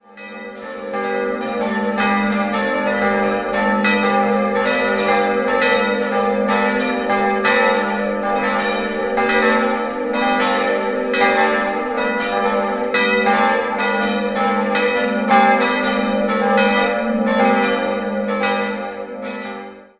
Von der mittelalterlichen Kirche ist nur noch der Treppengiebelturm erhalten, das Langhaus wurde im Jahr 1927 nach Plänen von Michael Kurz neu errichtet. 4-stimmiges Geläute: g'-a'-h'-d'' Die Glocken wurden 1947 von Karl Hamm in Regensburg gegossen.